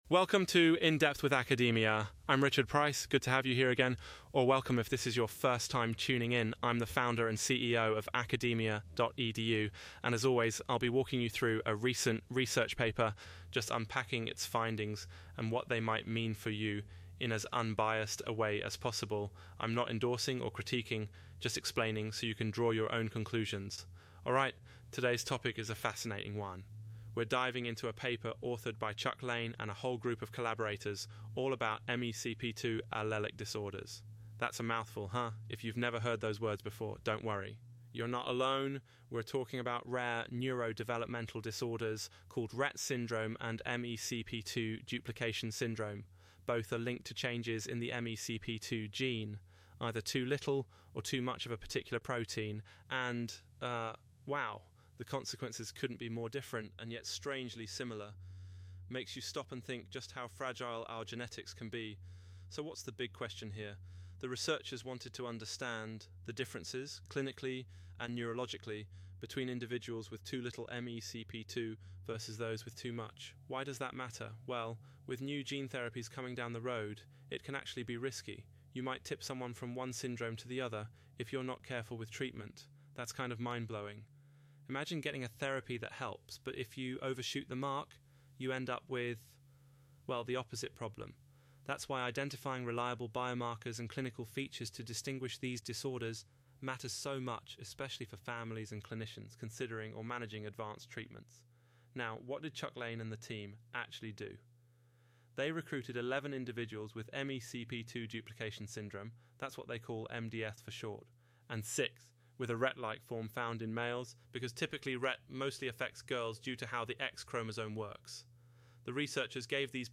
Automated Audio Summary